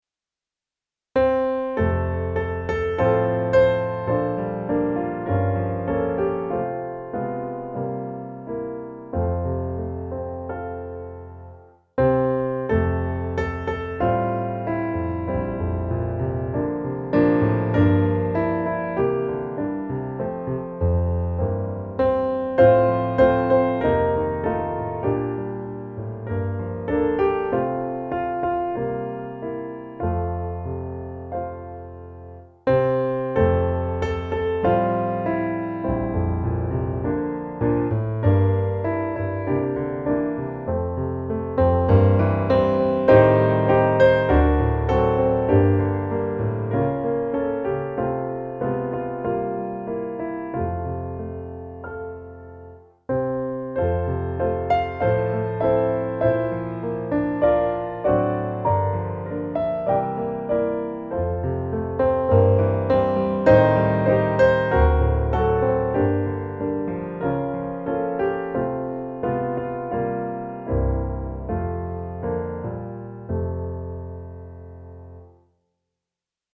Var dag är en sällsam gåva - musikbakgrund